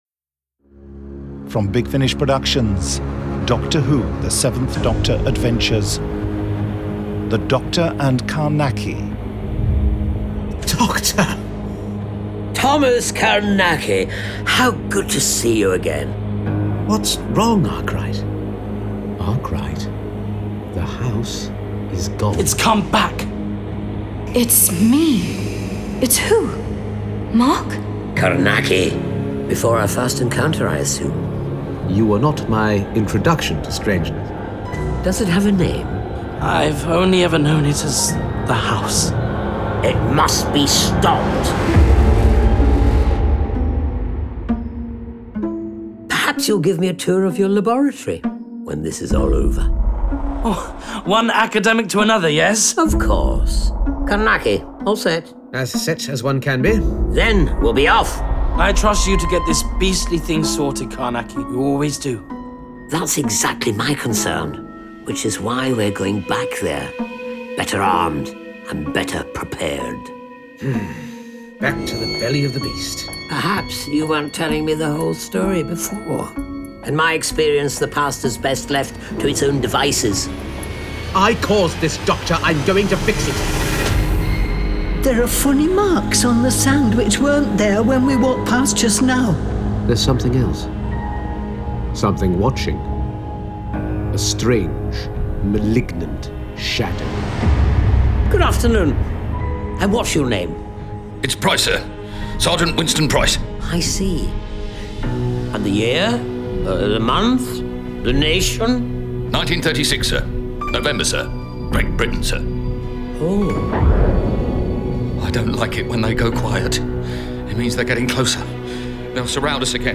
full-cast original audio dramas
Starring Sylvester McCoy
trailer